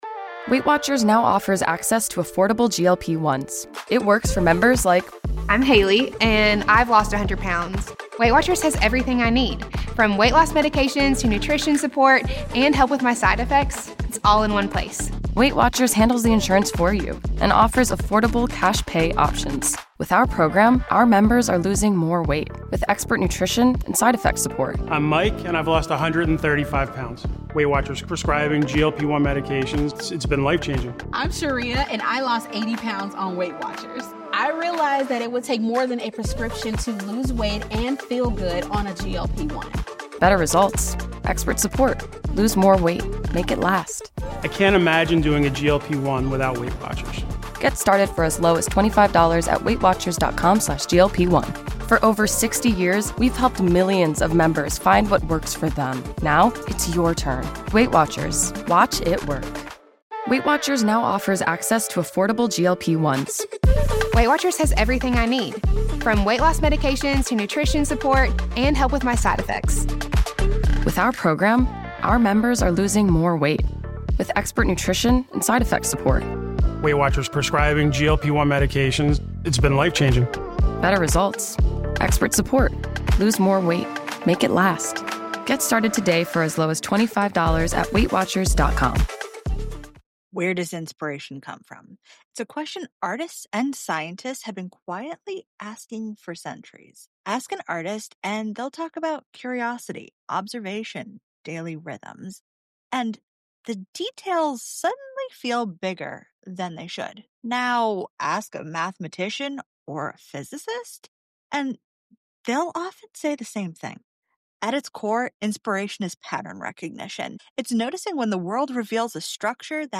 This conversation explores the fascinating intersection of math, physics, and art, highlighting how these disciplines inform and inspire one another.